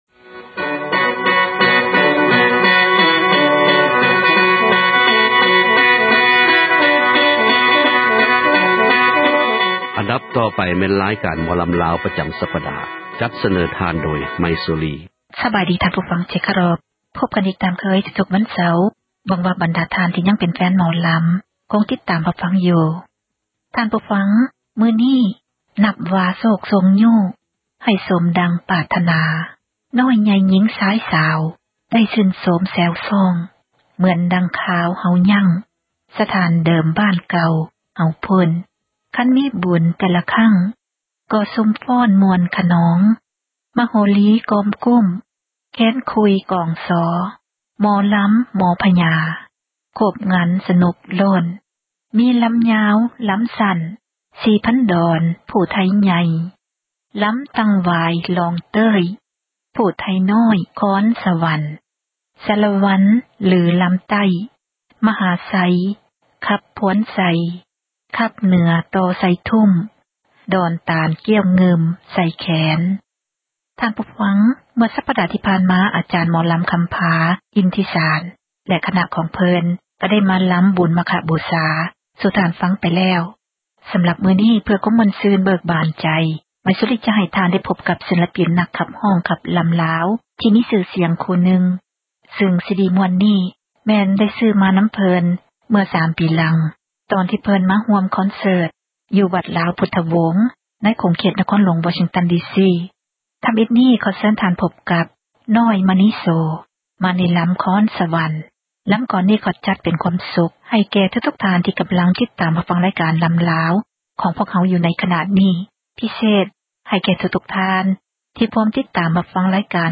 ຣາຍການ ໝໍລໍາລາວ ປະຈໍາສັປດາ ຈັດສເນີທ່ານ ໂດຍ